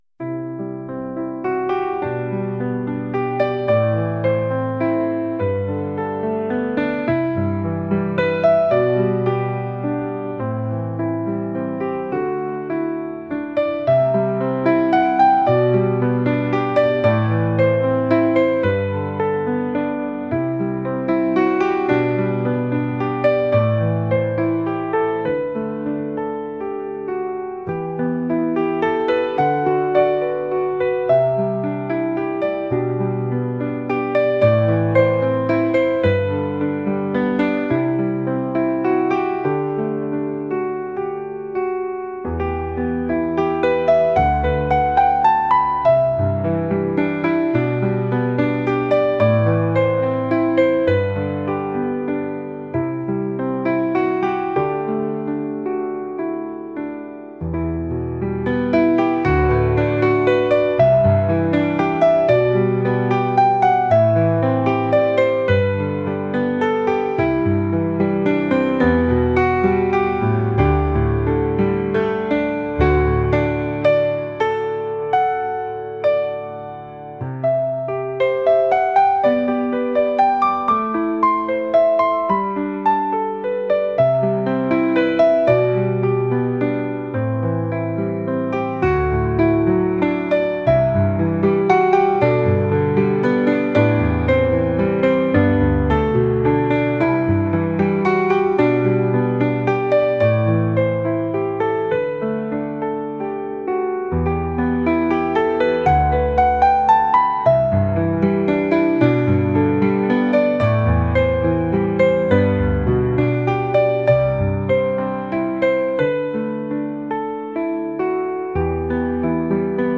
海の底で悠久の時を過ごすようなノスタルジーを感じるピアノ音楽です。